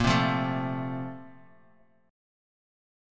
A#M7sus2 Chord